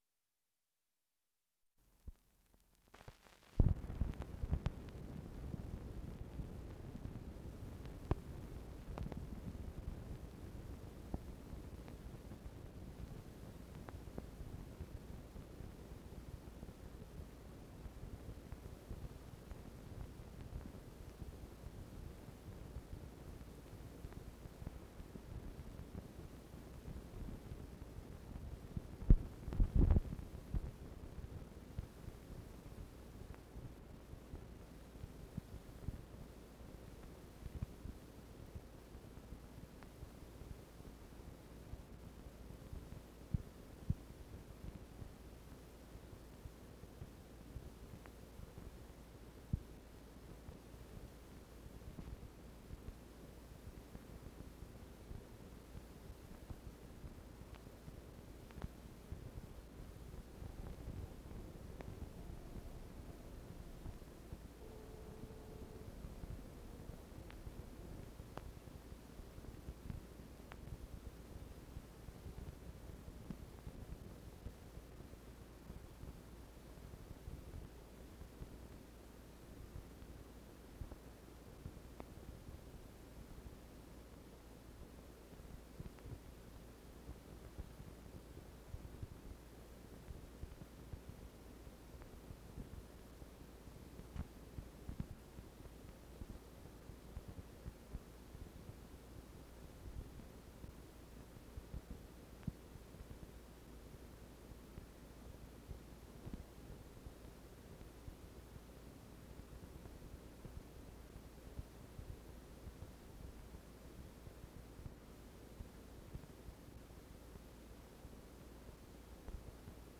College Concert Worship